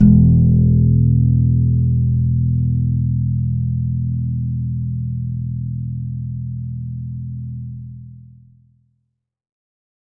52-str01-abass-e1.aif